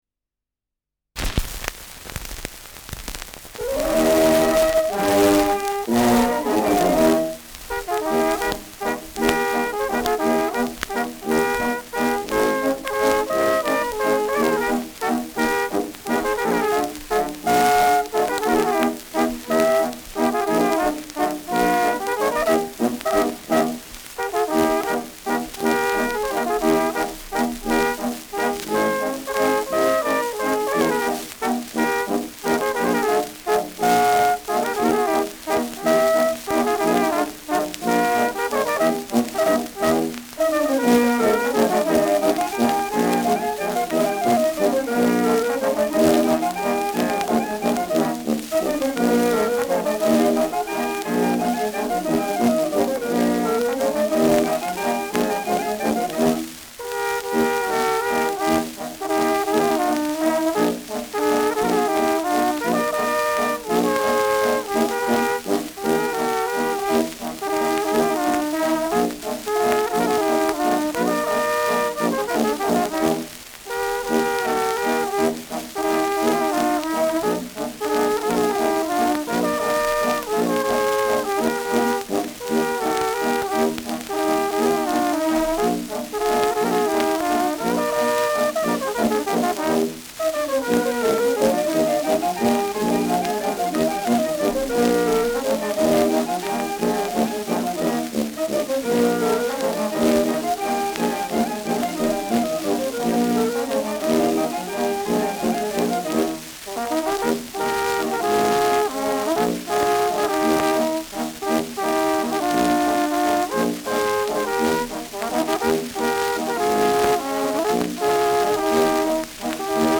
Schellackplatte
Stärkeres Grundrauschen : Gelegentlich leichtes bis starkes Knacken : Verzerrt an lauten Stellen : Leiern